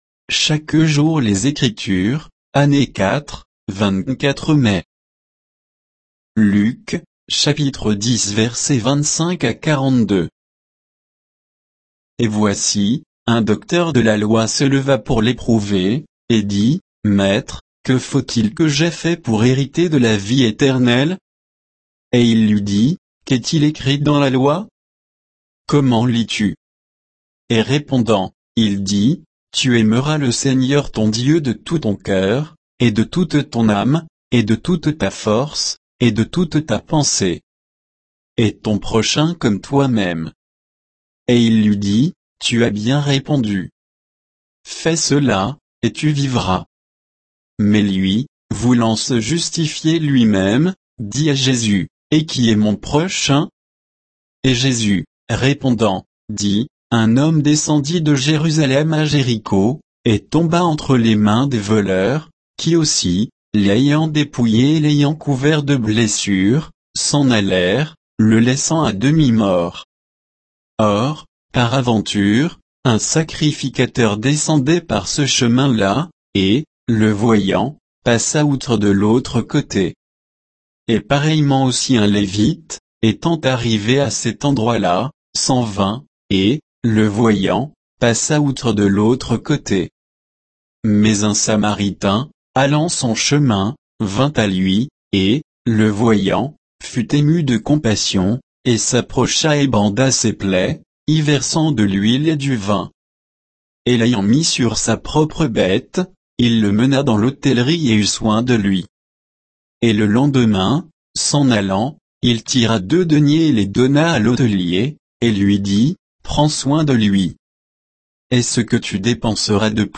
Méditation quoditienne de Chaque jour les Écritures sur Luc 10, 25 à 42